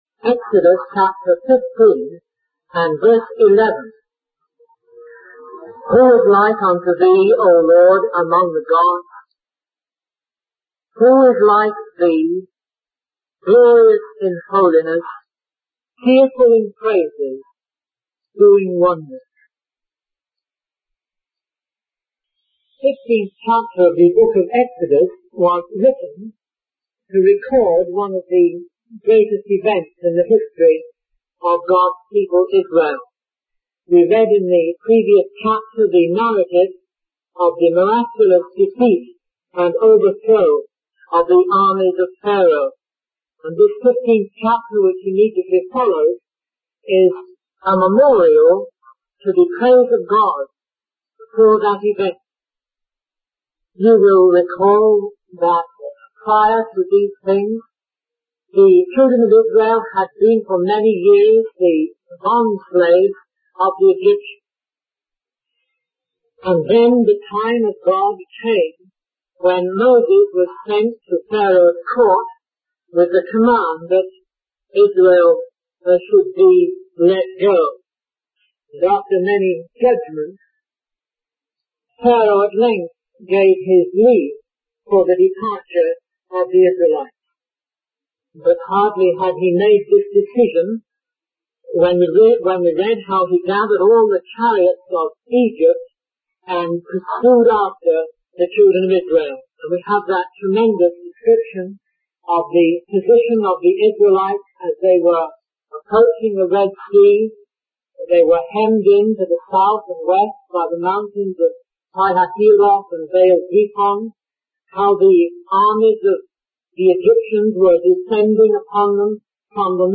In this sermon, the speaker discusses the story of the Israelites' escape from Egypt and their journey to the Red Sea.